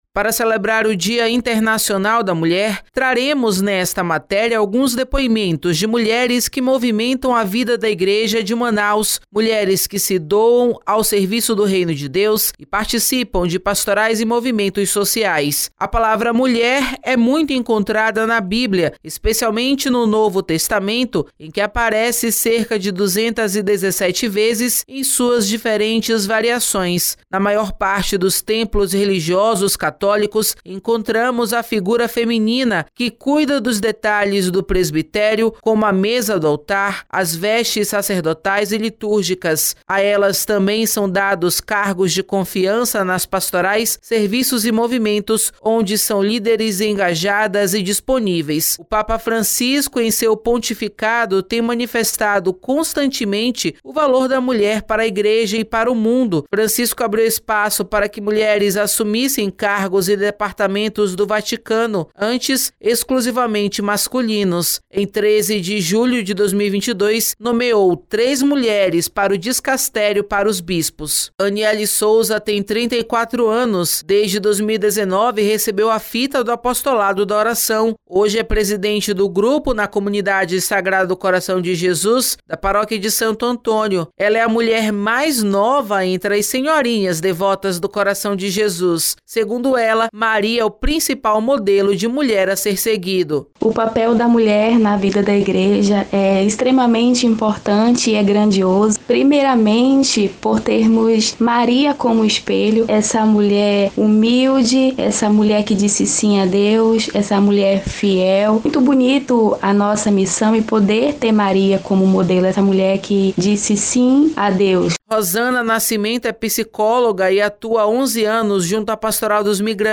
Para celebrar o Dia Internacional da Mulher, traremos nesta matéria alguns depoimentos de mulheres que movimentam a vida da igreja de Manaus. Mulheres que se doam ao serviço do Reino de Deus e participam de pastorais e movimentos sociais.